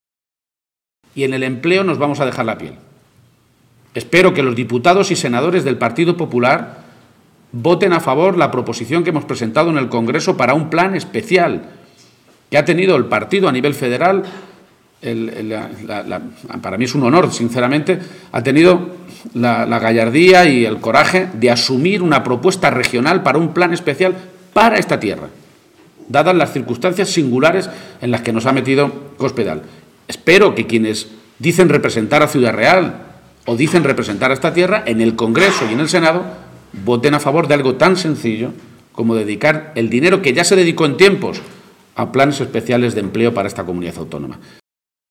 García-Page se pronunciaba de esta manera esta mañana, en una comparecencia ante los medios de comunicación en la que insistía en que el episodio de este fin de semana ha vuelto a poner de manifiesto «lo que de verdad le interesa a Cospedal, y a lo que en realidad ha estado dedicado estos cuatro años: A los líos internos del PP».